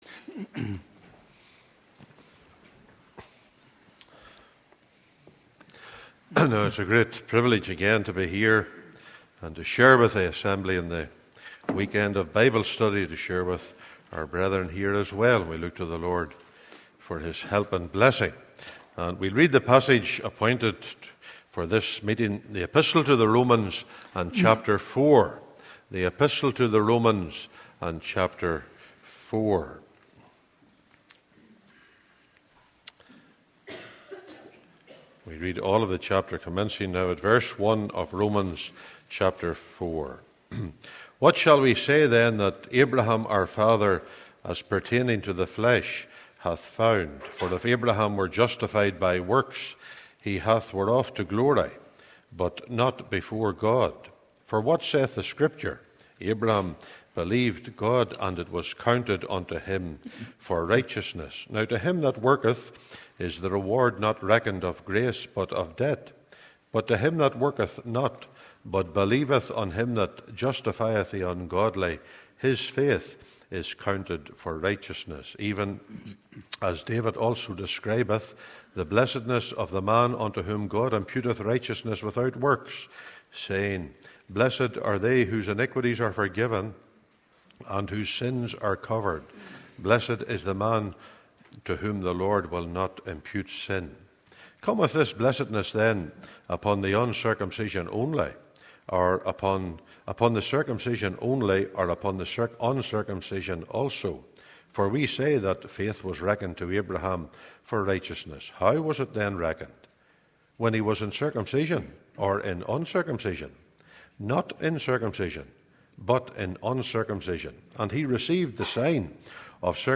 Bible Reading Conference 2019